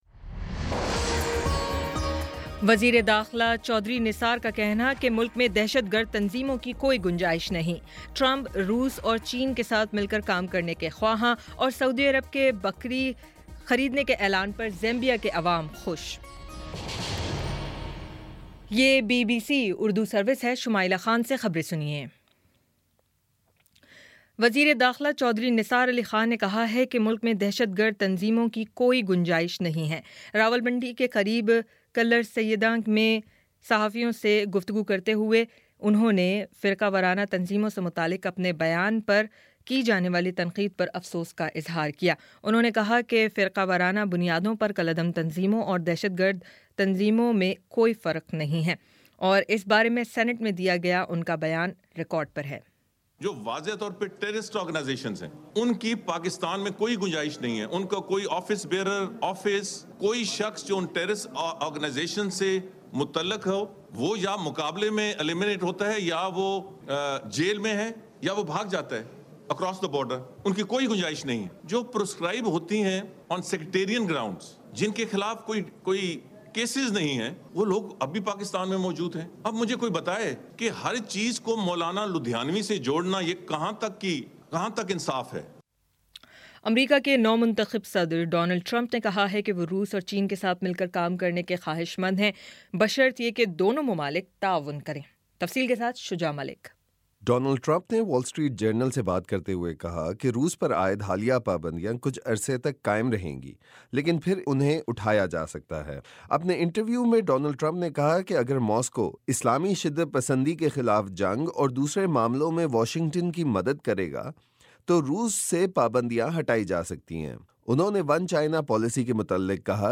جنوری 14 : شام پانچ بجے کا نیوز بُلیٹن